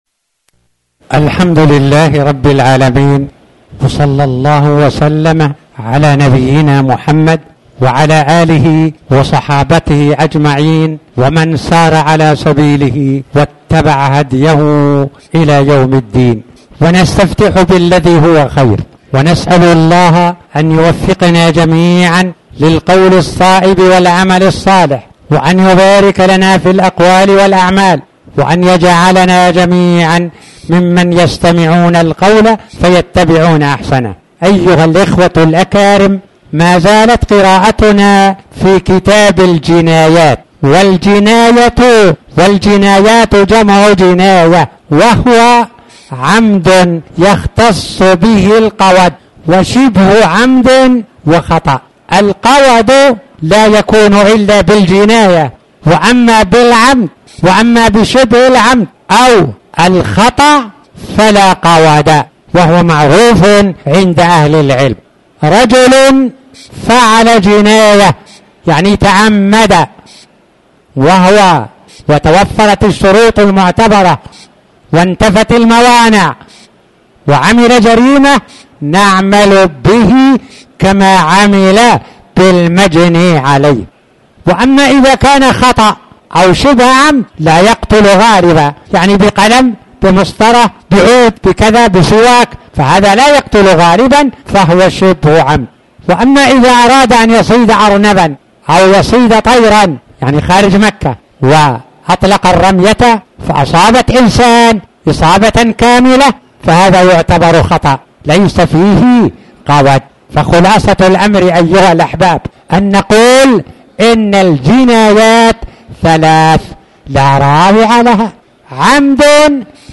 تاريخ النشر ٢٤ رجب ١٤٣٩ هـ المكان: المسجد الحرام الشيخ